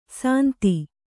♪ sānti